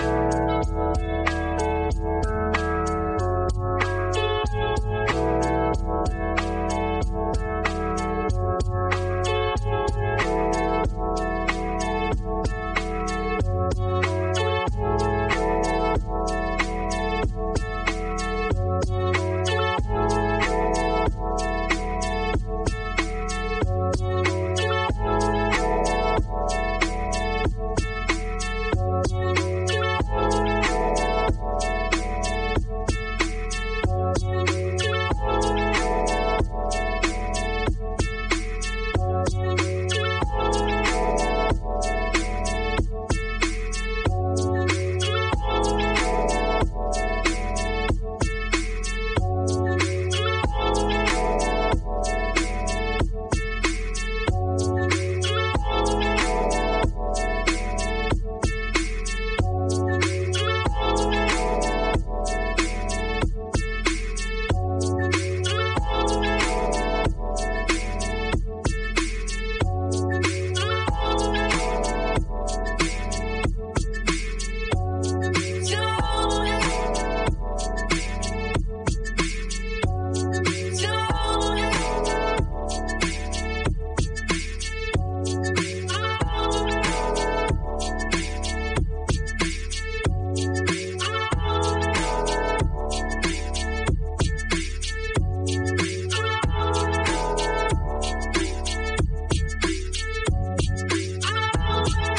riffusion-app - Stable diffusion for real-time music generation (web app)
sunrise_dj_set_to_hard_synth.mp3